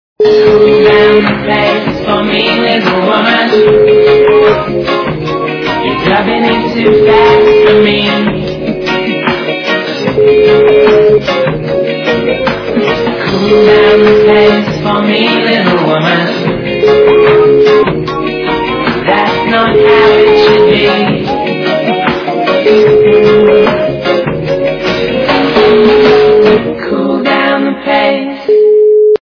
западная эстрада
качество понижено и присутствуют гудки